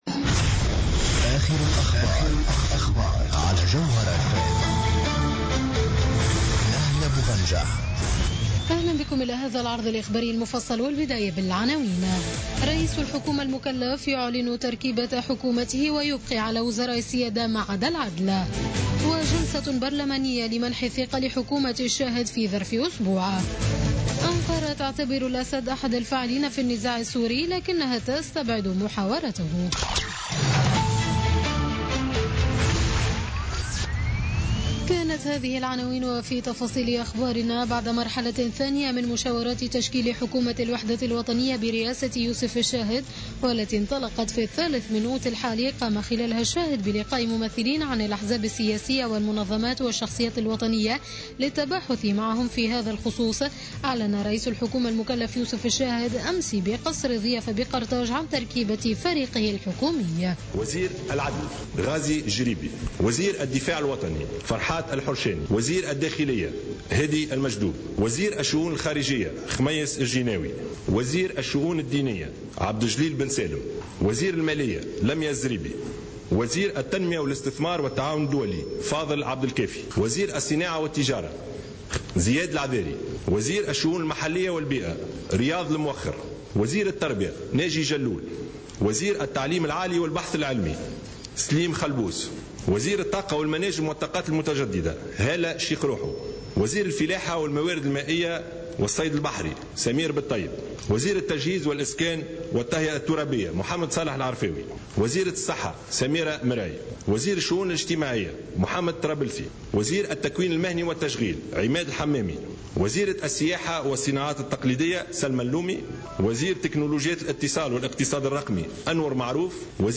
نشرة أخبار منتصف الليل ليوم الأحد 21 أوت 2016